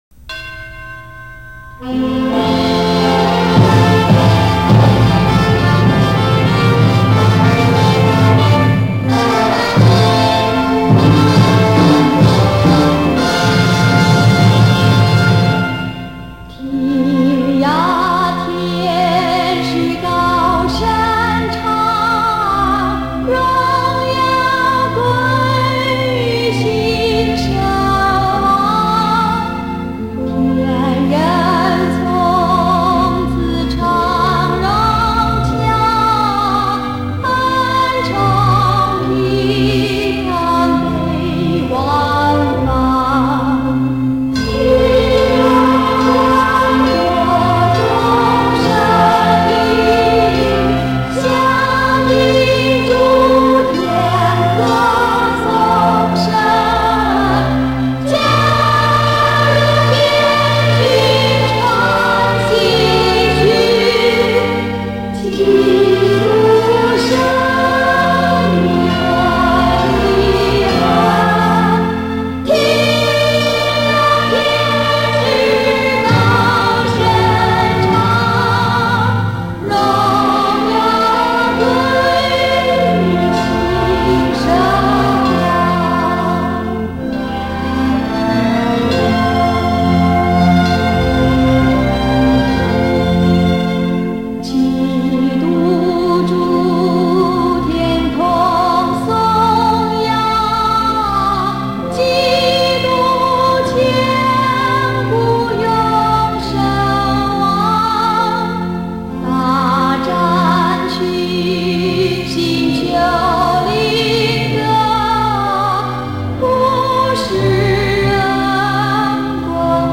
音乐类型： 流行, 圣诞, 贺年　    　.
听她唱高音的圣歌，咬字清晰，歌声高吭嘹亮，所表现出来的气势与声　.